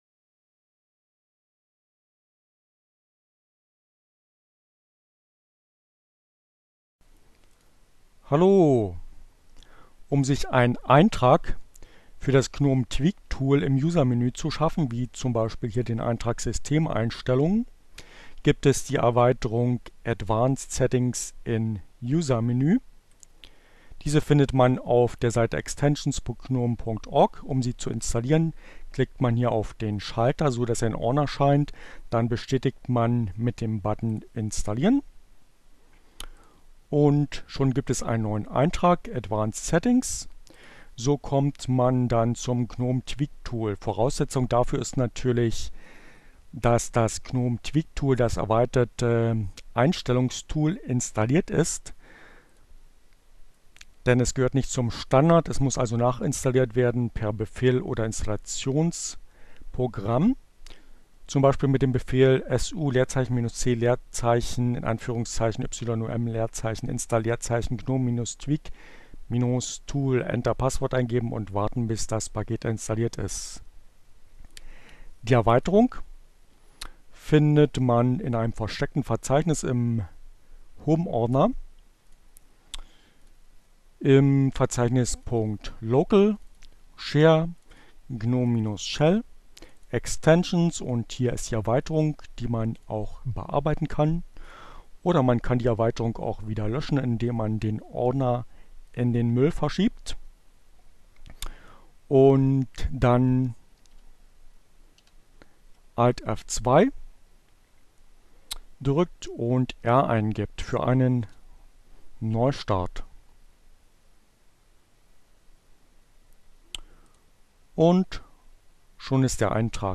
ohne Musik , screencast